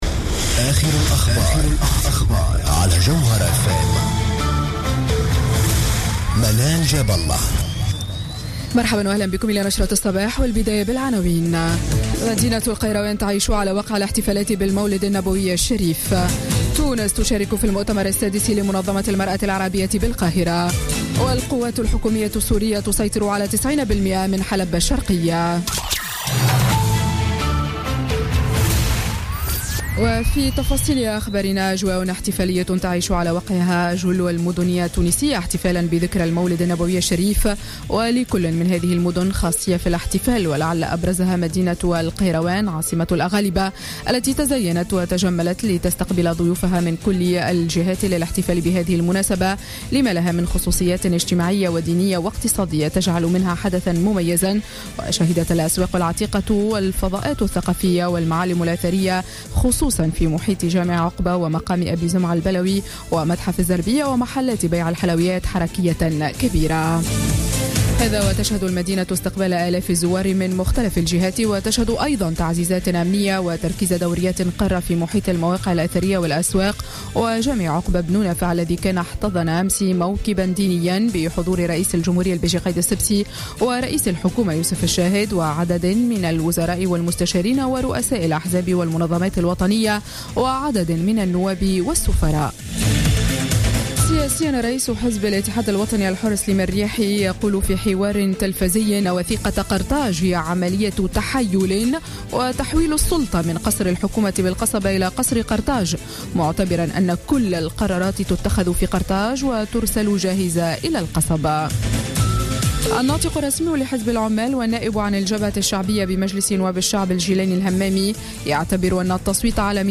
نشرة أخبار السابعة صباحا ليوم الاثنين 12 ديسمبر 2016